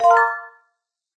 chime_1.ogg